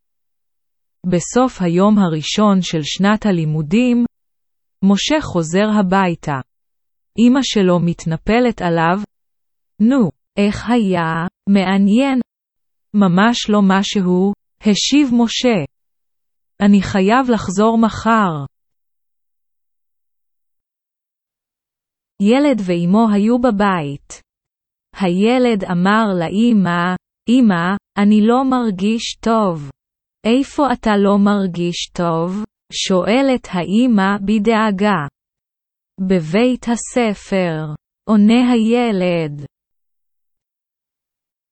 Texte Hébreu lu à haute voix à un rythme lent !